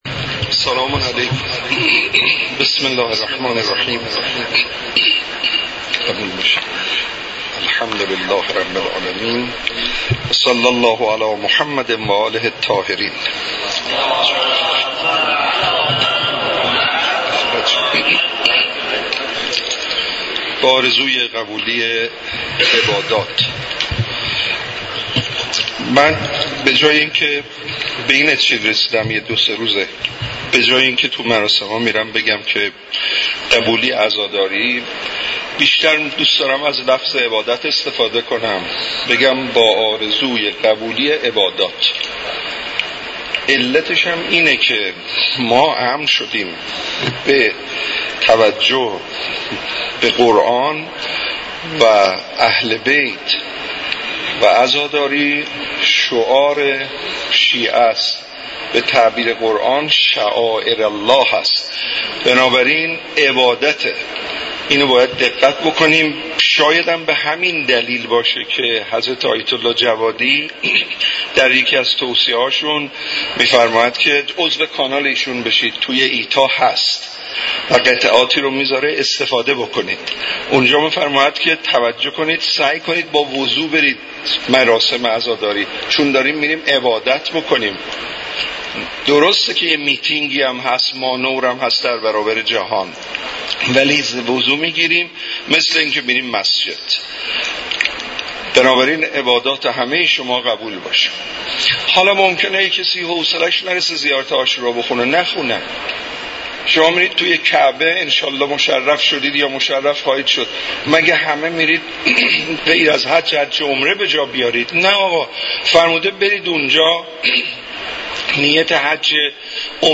مراسم عزاداری حضرت اباعبدالله الحسین علیه السلام همراه با قرائت زیارت عاشورا ، سخنرانی و مدّاحی در دانشگاه کاشان برگزار شد.